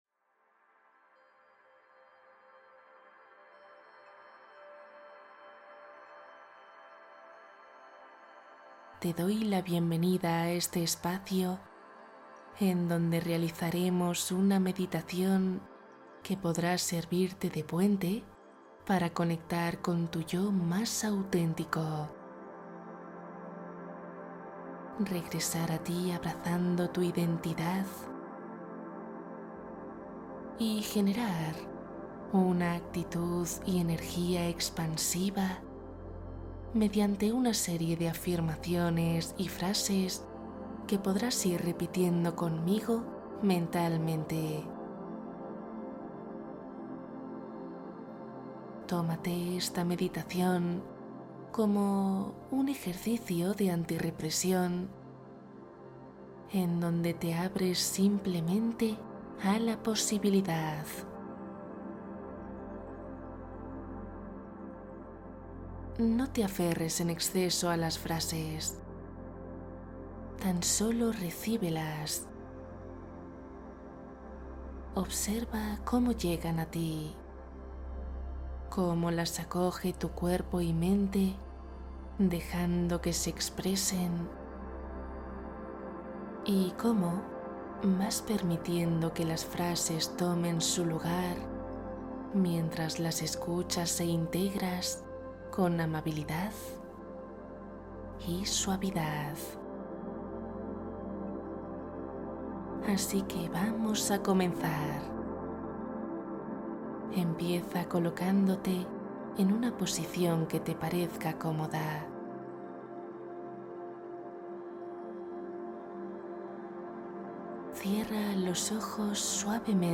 Yo Soy  Afirmaciones y meditación para dormir conectando contigo